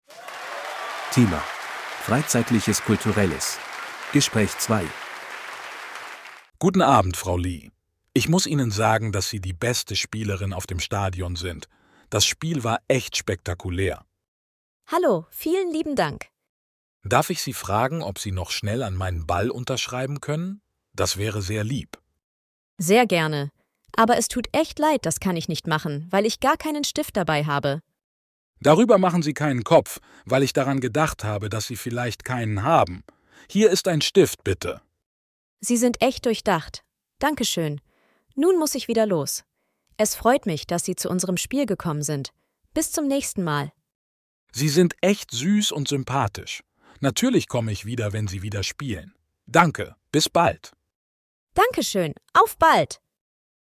Audio text conversation 2:
A2-Kostenlose-R-Uebungssatz-3-Freizeitliches-Kulturelles-Gespraech-2.mp3